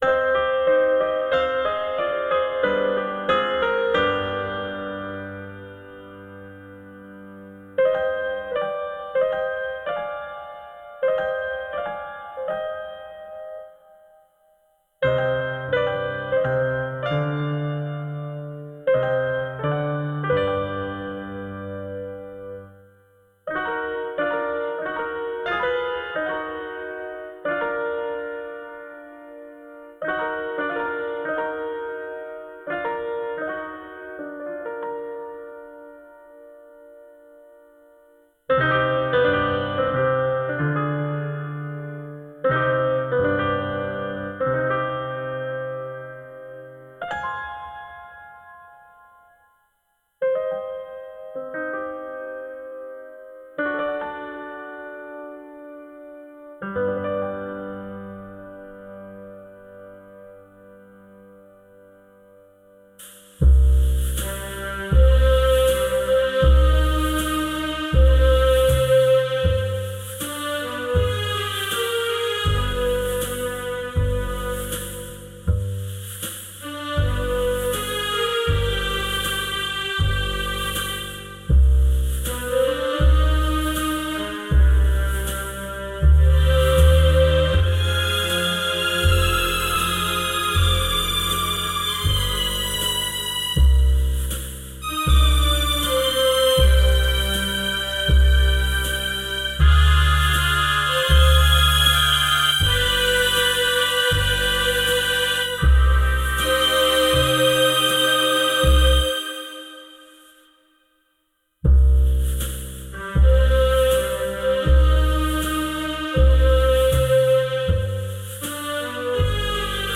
This is an instrumental backing track cover.
Key – C
Without Backing Vocals
No Fade